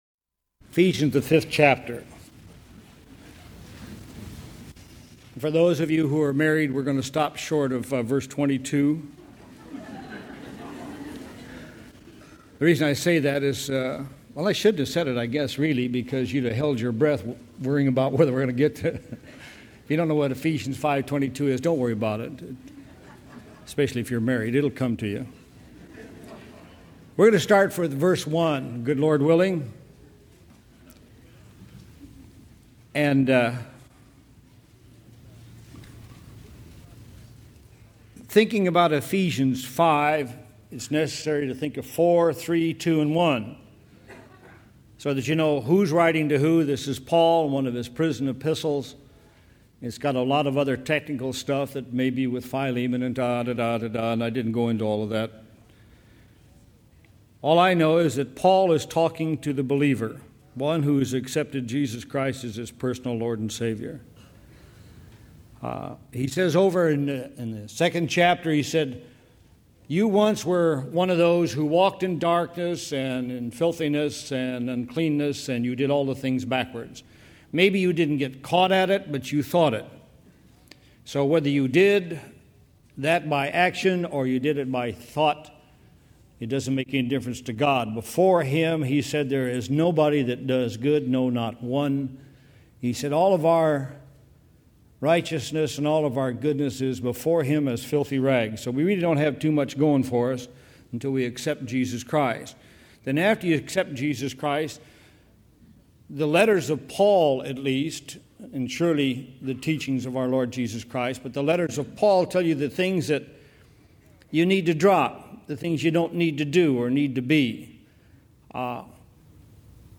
Walking in Love download sermon mp3 download sermon notes Welcome to Calvary Chapel Knoxville!